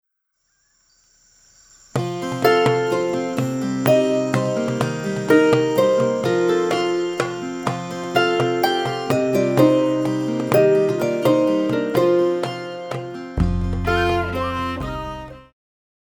Pop
Cello
Instrumental
World Music,Electronic Music
Only backing